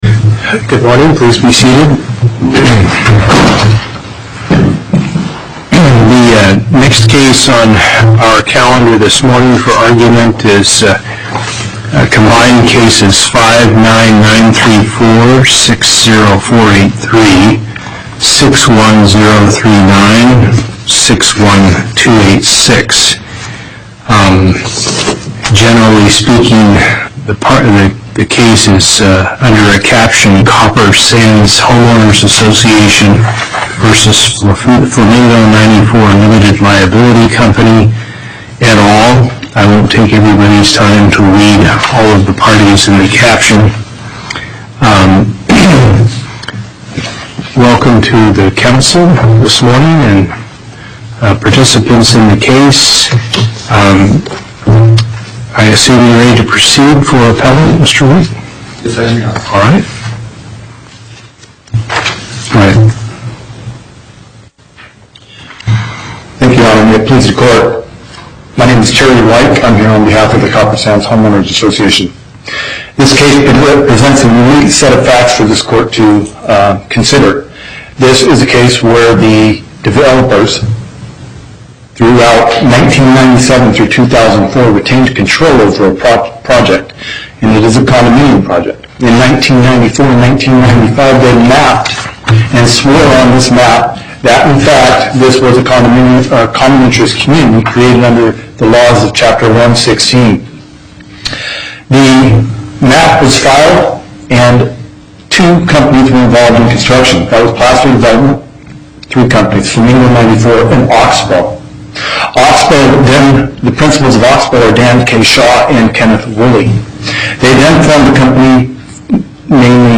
Location: Las Vegas Before the Southern Nevada Panel, Justice Hardesty Presiding